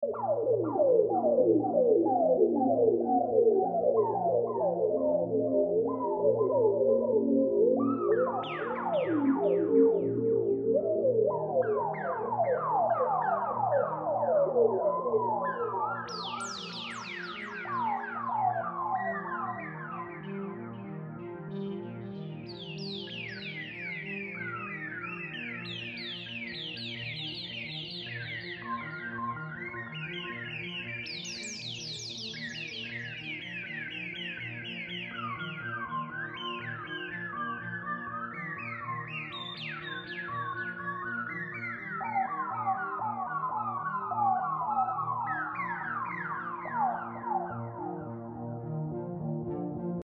Synth Whale Birds spreading waves